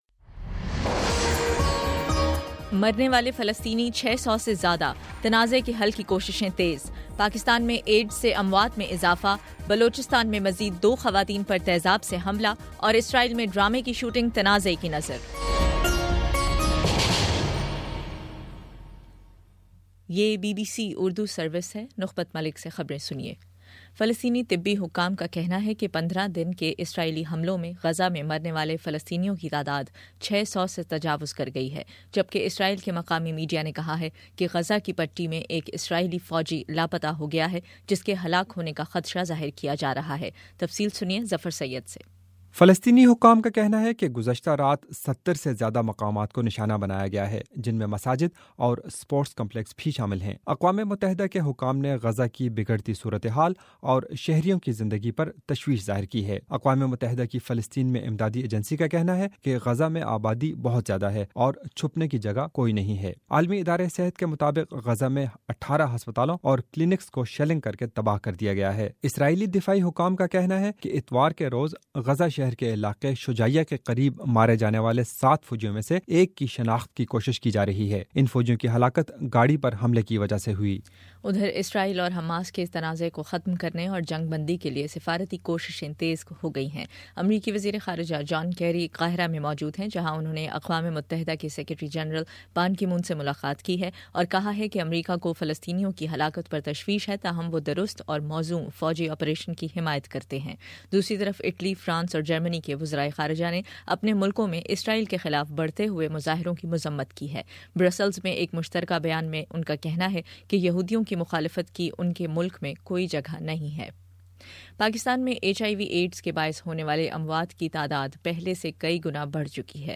بائیس جولائی: شام 6 بجے کا نیوز بُلیٹن